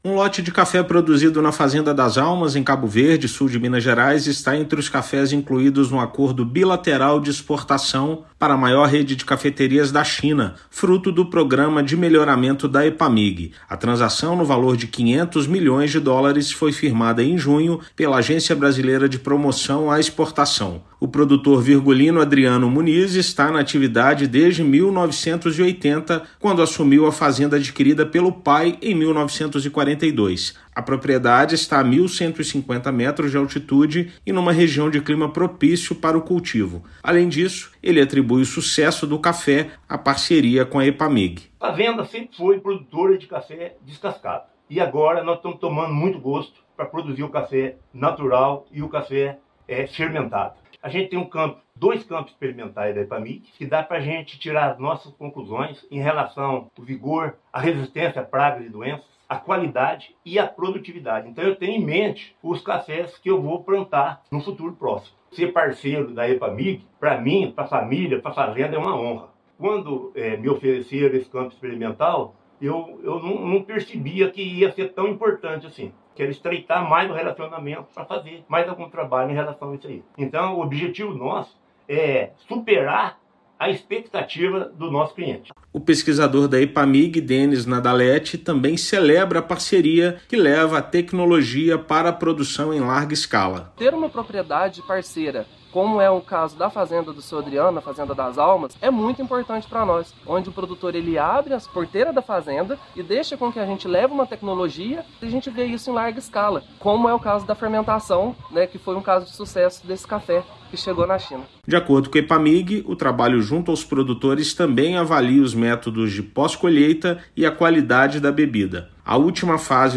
Fazenda das Almas, em Cabo Verde, no Sul de Minas, conta com duas unidades demonstrativas de pesquisas da Epamig, empresa do Governo de Minas. Ouça matéria de rádio.